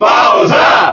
Category:Bowser (SSBB) Category:Crowd cheers (SSBB) You cannot overwrite this file.
Bowser_Cheer_German_SSBB.ogg